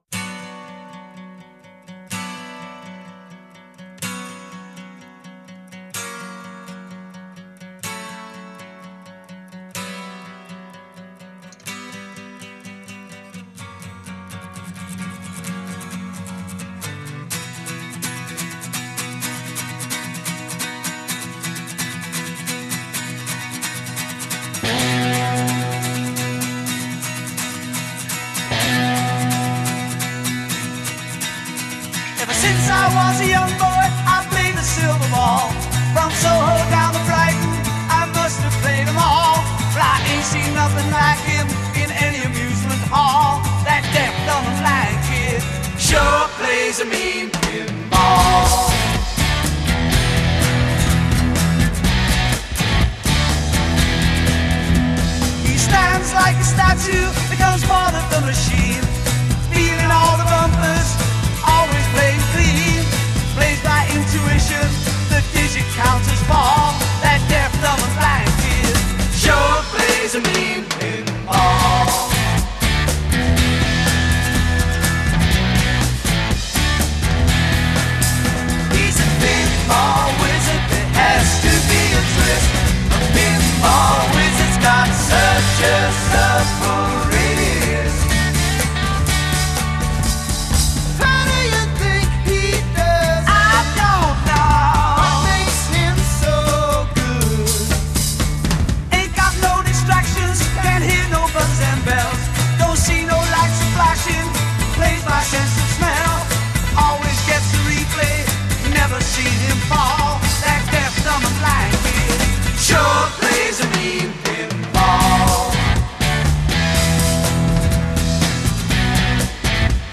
Recorded at I.B.C. Studios, London
Verse 8 Solo vocal; harmonized vocals at hook. a
Bridge 4 Add drums.
Chorus 6 3-part harmonies c
Outro 6 End on subdominant.